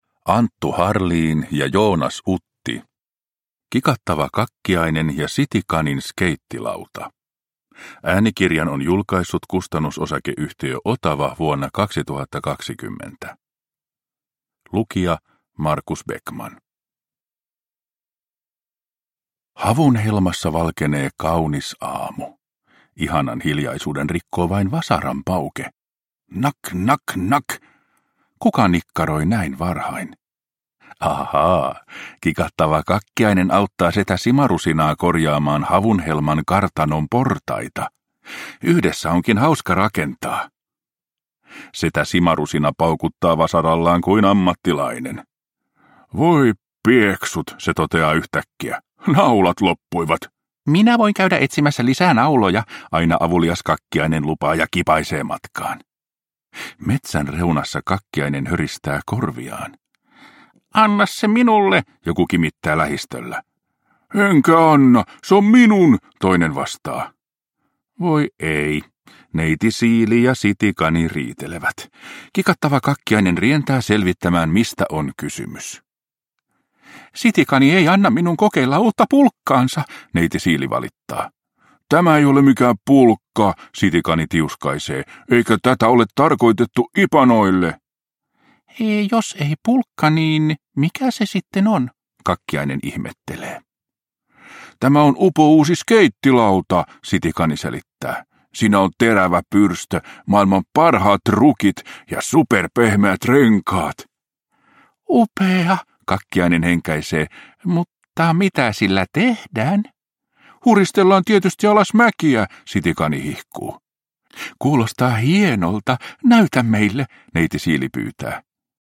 Kikattava Kakkiainen ja City-Kanin skeittilauta – Ljudbok – Laddas ner